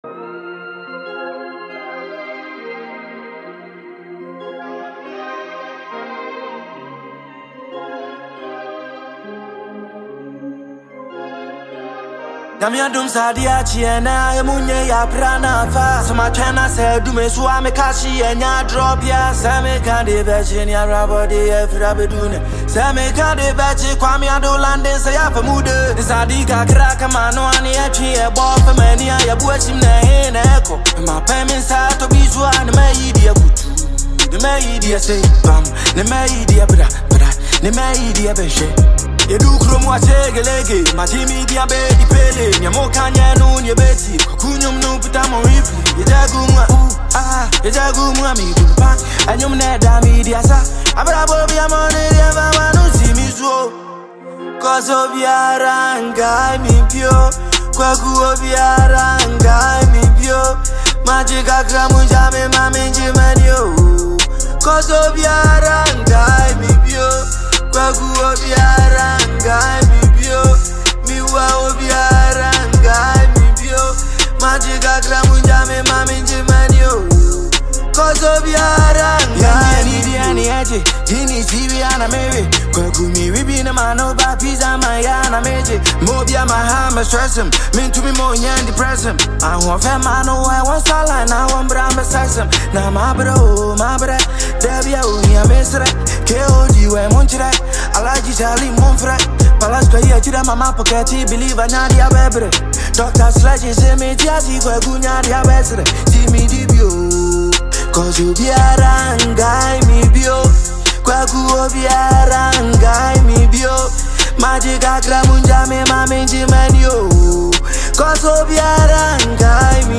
Ghanaian award-winning rapper